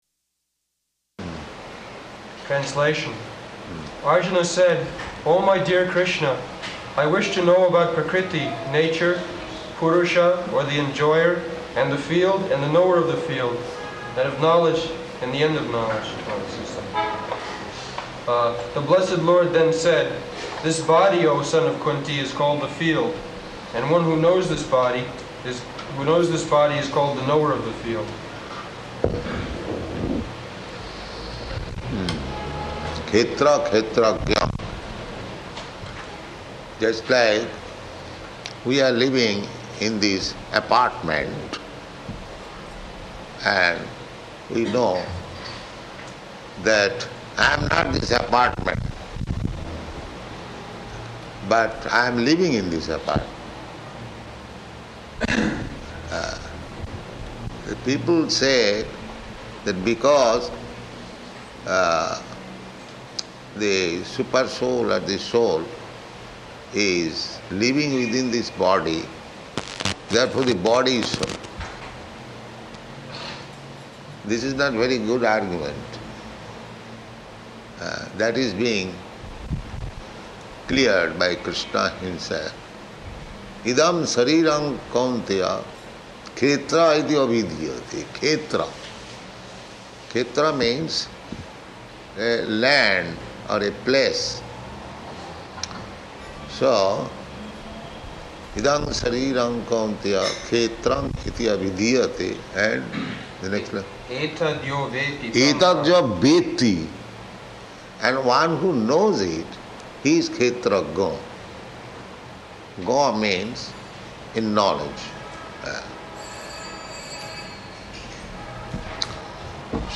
Type: Bhagavad-gita
Location: Bombay